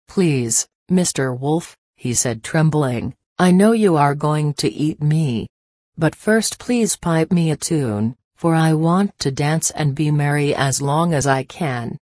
به همراه فایل صوتی تلفظ انگلیسی